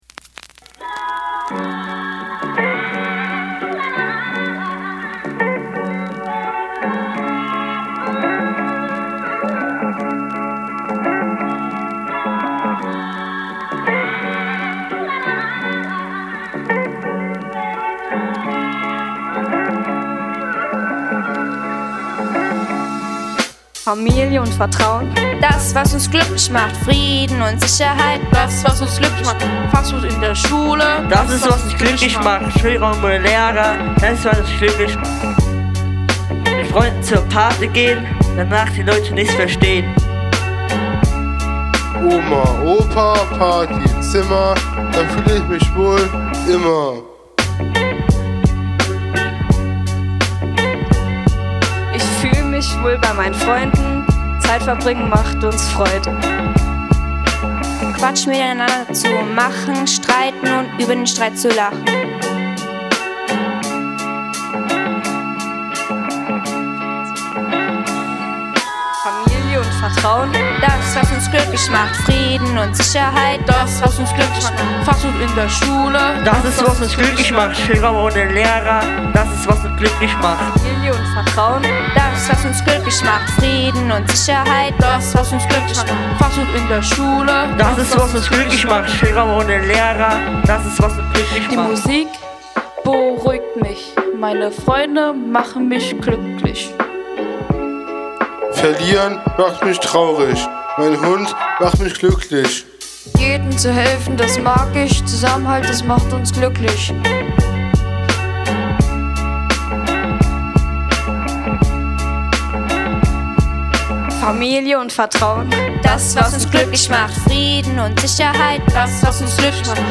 Die Schülerinnen und Schüler der Klasse L8/9b arbeiteten dabei zwei Tage lang intensiv an einem eigenen Song. Der Text stammte vollständig von den Jugendlichen selbst – ehrlich, kreativ und voller Energie. Gemeinsam mit einem eingängigen Beat entstand so ein Lied, das ihre Gedanken und Gefühle eindrucksvoll zum Ausdruck brachte.